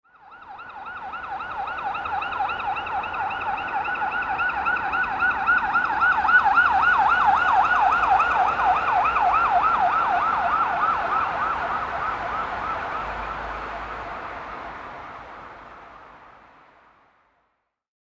Police-or-ambulance-siren-passing-on-city-street-sound-effect.mp3